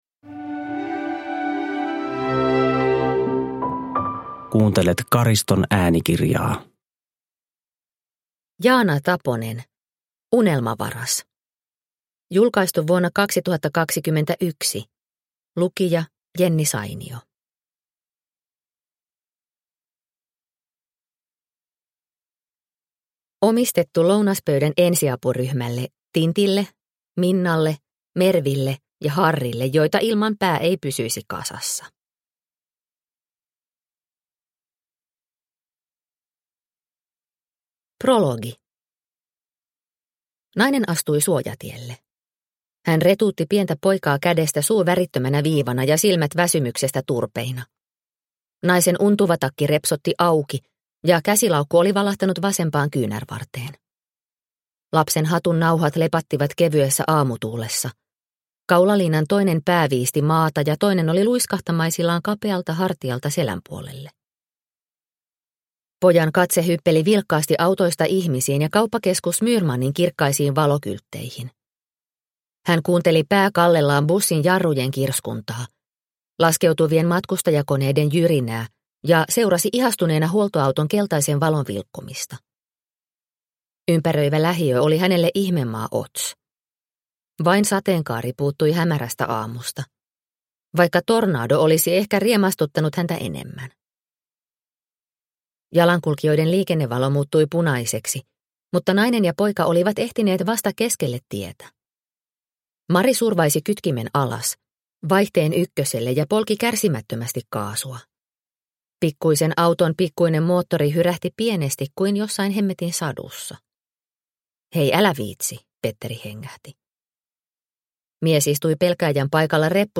Unelmavaras (ljudbok) av Jaana Taponen